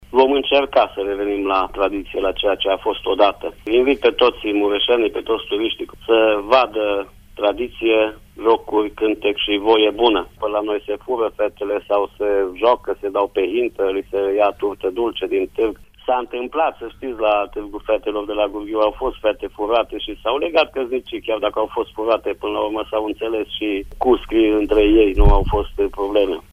Târgul avea atunci menirea de a găsi soți tinerelor din localitate. Primarul comunei Gurghiu, Laurențiu Dumitru Boar, spune că anul acesta vor încerca să reînvie tradițiile: